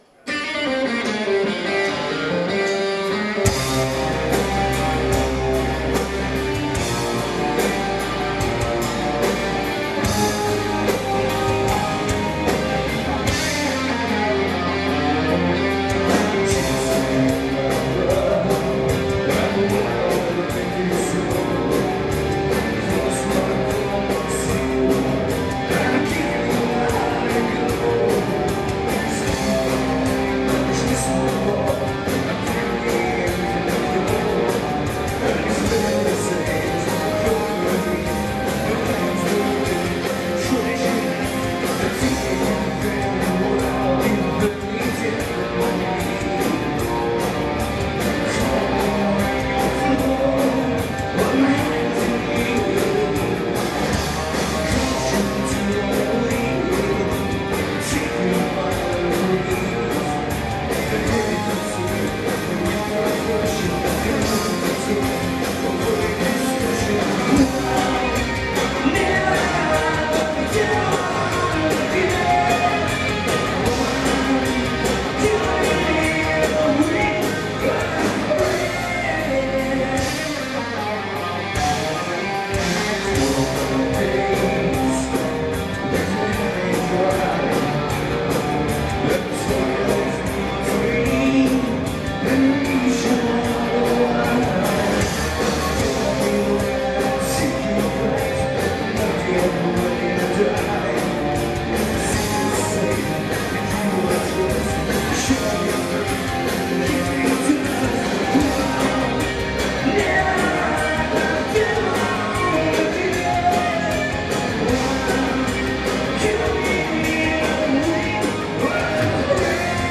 Amsterdam 26.09.2005